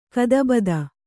♪ kadabada